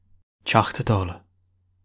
A Teachta Dála ( /ˌtjɒxtə ˈdɔːlə/ TYOKH-tə DAW-lə, Irish: [ˌtʲaxt̪ˠə ˈd̪ˠaːlˠa]
Ga-Teachta_Dála.ogg.mp3